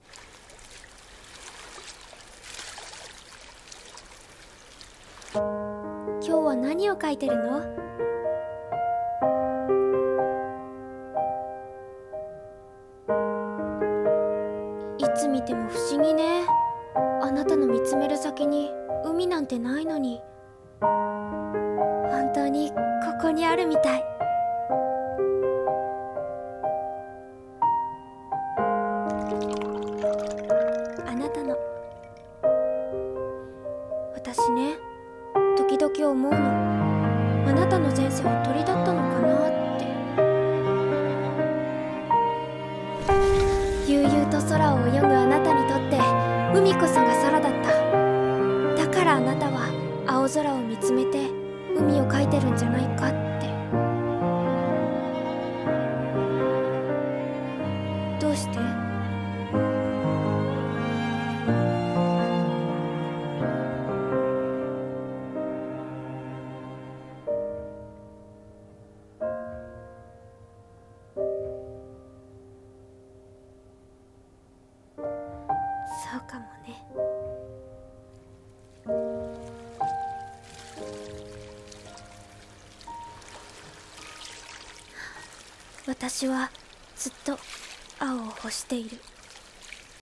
【コラボ用】二人声劇「蒼い鯨」